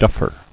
[duff·er]